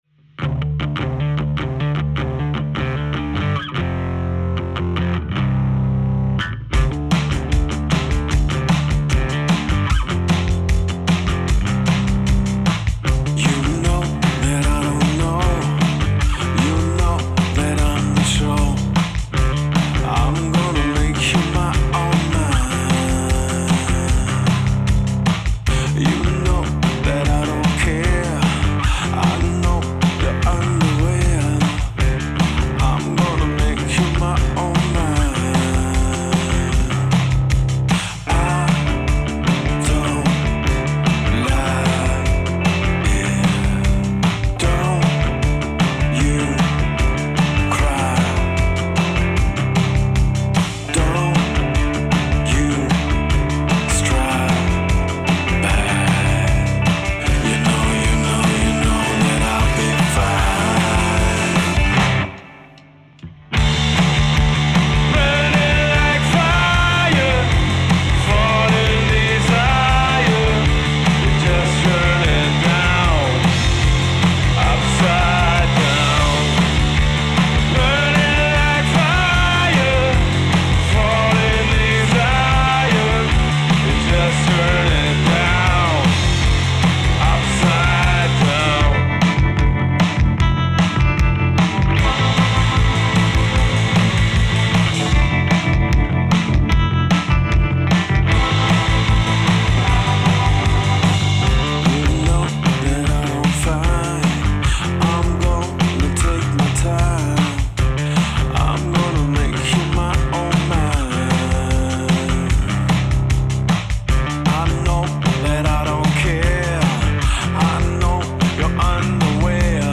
Wir sind die Band DI6E.
Die Spuren wurden in unserem Proberaum/Studio selbst aufgenommen.
BPM 152 44,1khz 32bit float.